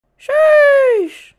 Sheesh Effect1